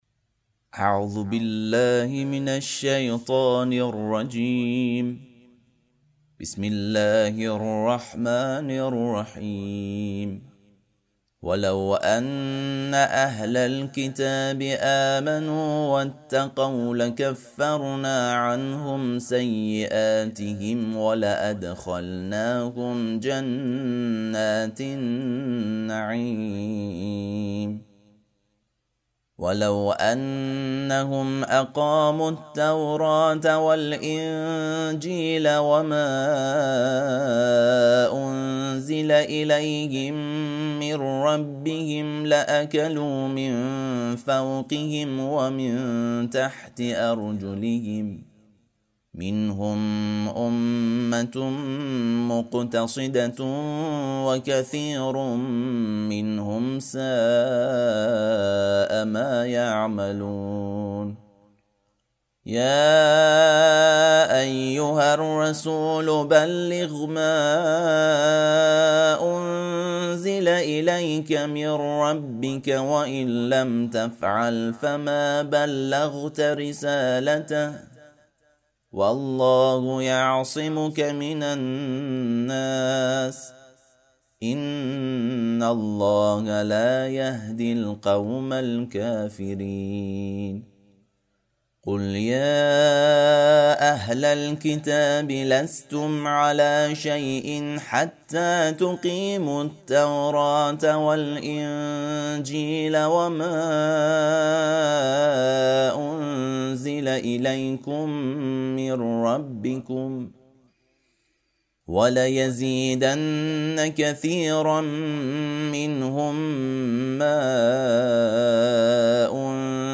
ترتیل صفحه ۱۱۹ سوره مبارکه مائده(جزء ششم)